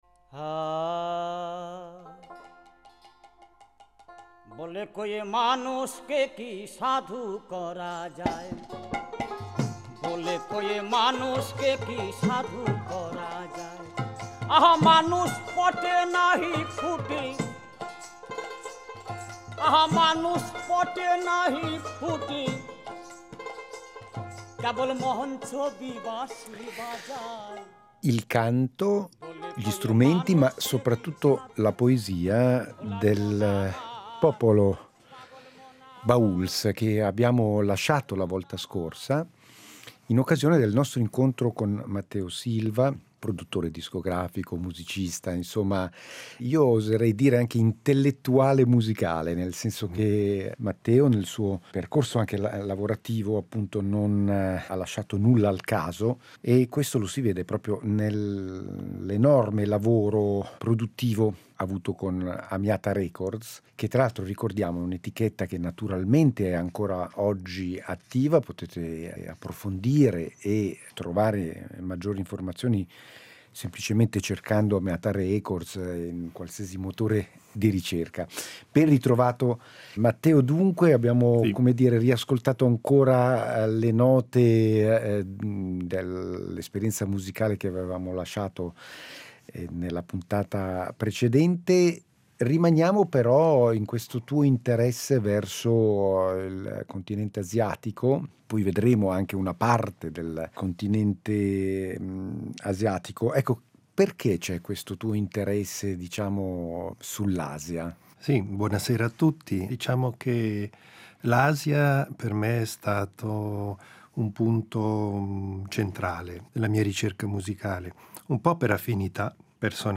Musicalbox